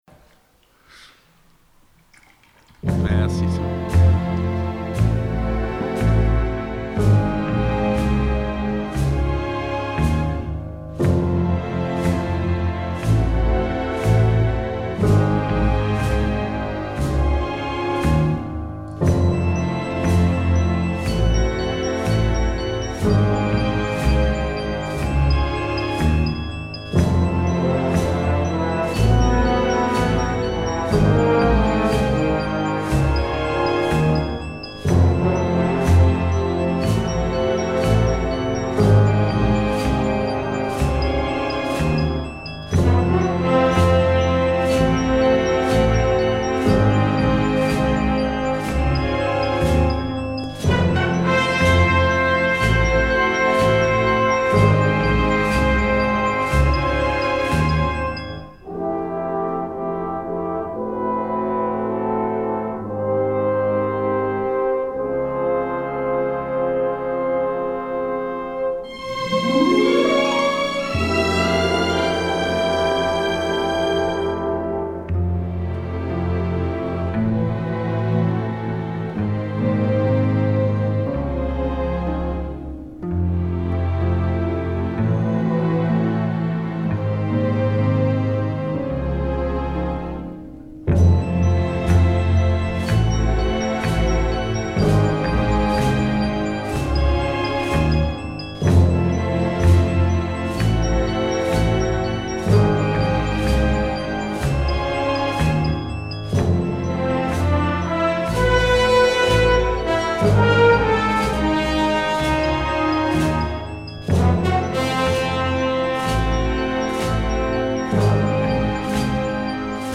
ITW
lors du vernissage de l'exposition